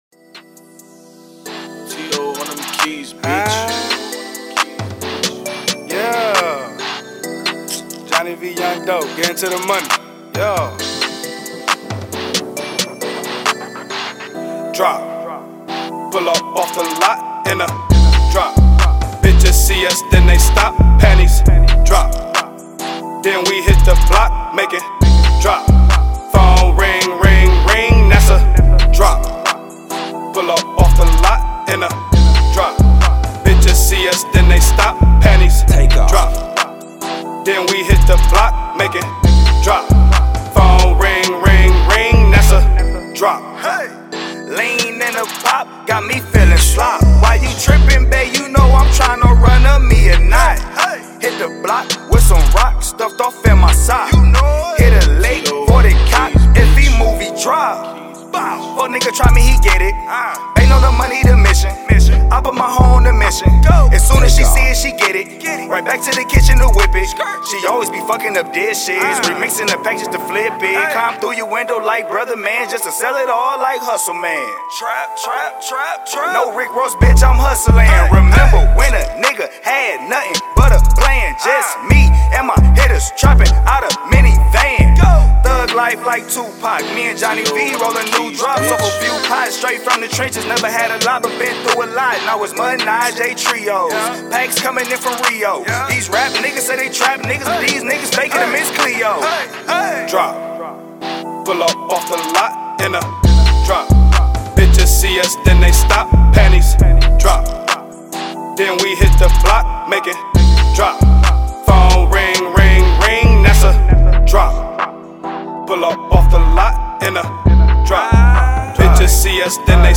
Hiphop
summer time street banger